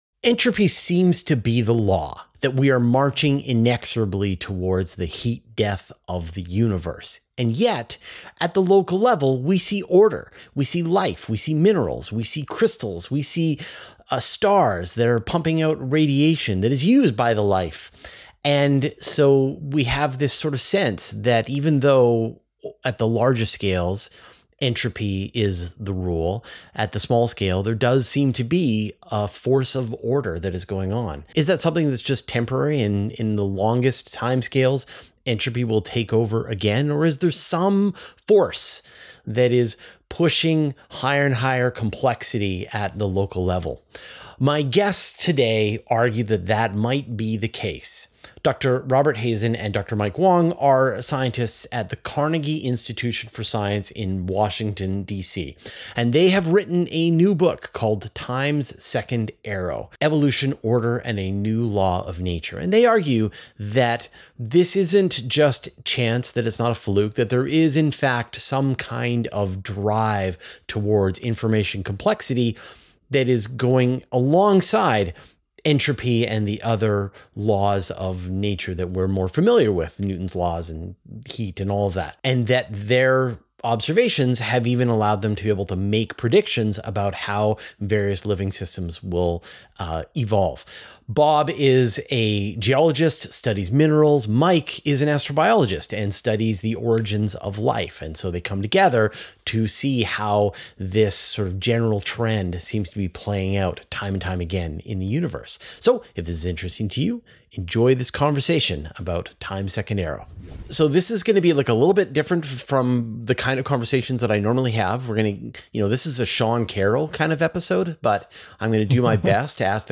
Diving into this concept in this interview.